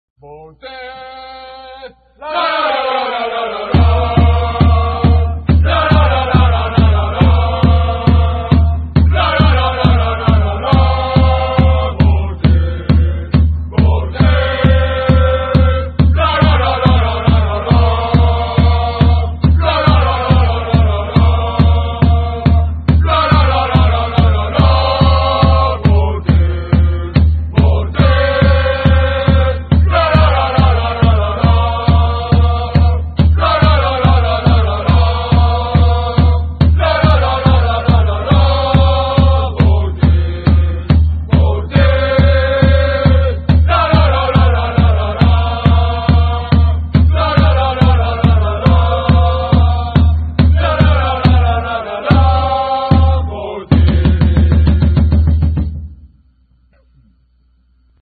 チームのチャント